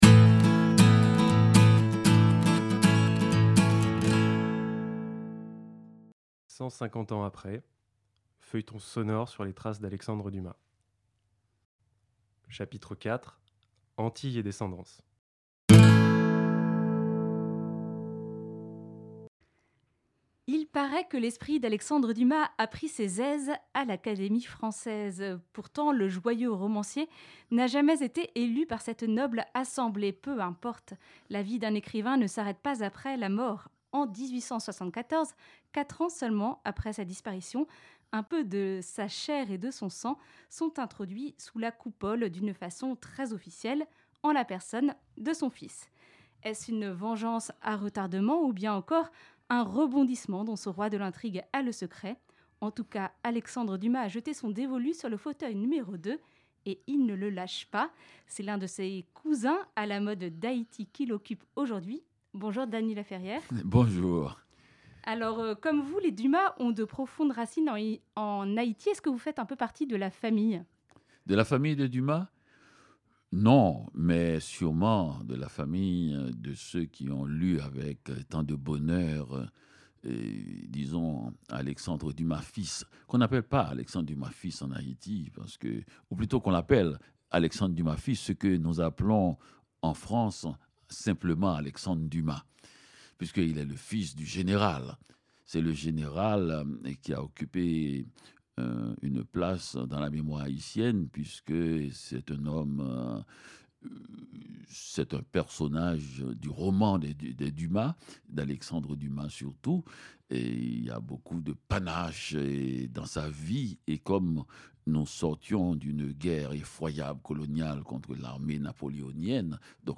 150 ans après : Un feuilleton sonore sur les traces d’Alexandre Dumas
C’est l’un de ses cousins à la mode d’Haïti qui l’occupe aujourd’hui. Nous écoutons Dany Laferrière...